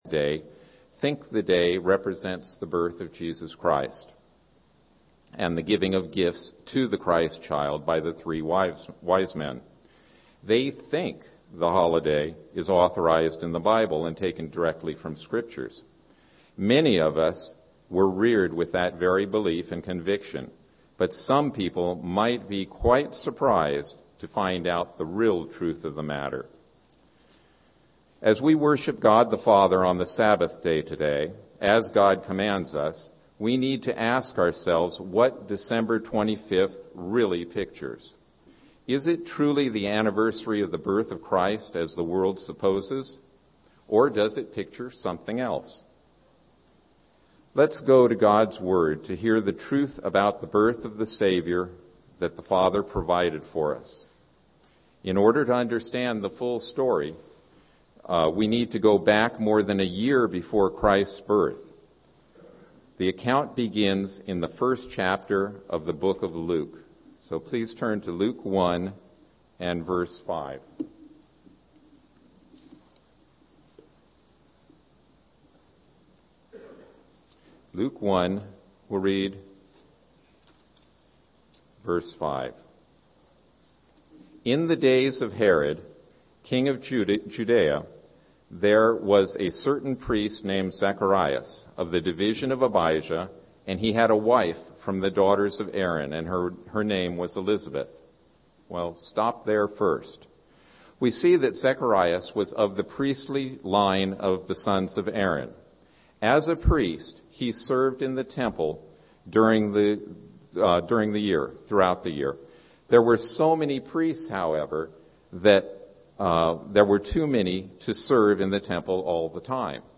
UCG Sermon Notes Is Christmas Christian?